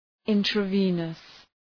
{,ıntrə’vınəs}
intravenous.mp3